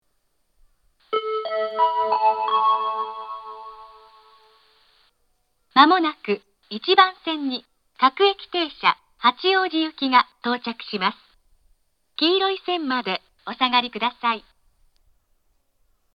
東海道型(女性)
接近放送
収録時はなぜかノイズが入りませんでした。